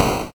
explosion_small.ogg